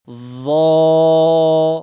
Harflerin üzerine tıklayarak dinleyebilirsiniz Uzatan Elif Kendinden önce gelen harfi Kalın olursa A ince olursa E sesiyle bir elif miktarı uzatarak Okutur